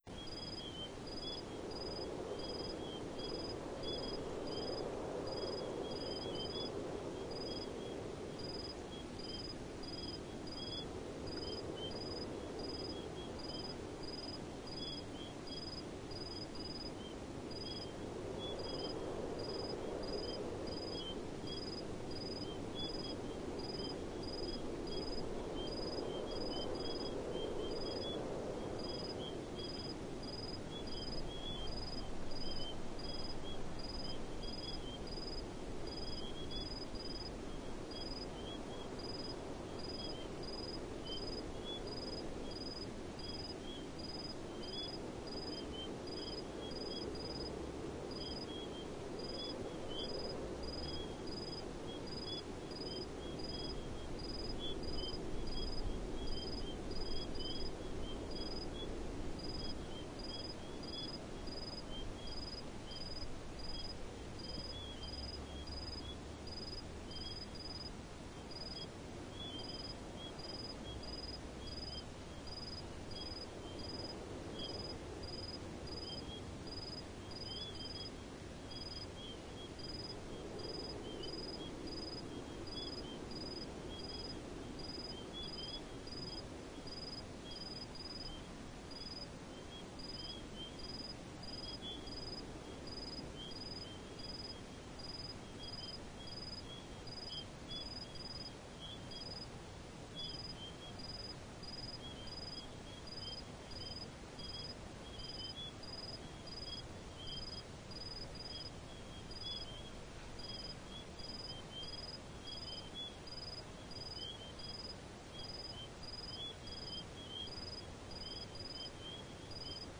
• crickets chirping in desert.wav
crickets_chirping_in_desert_15H.wav